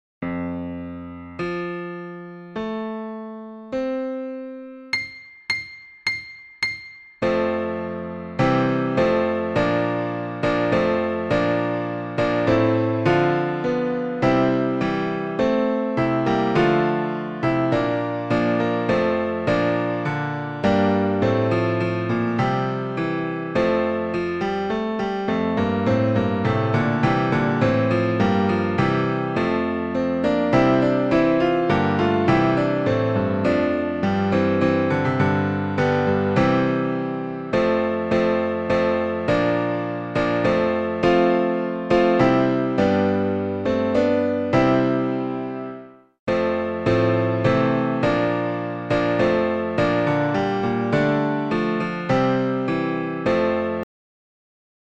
Audition Cut Audio Files
Mid-State Men (Hassler)